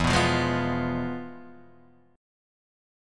Fdim/E chord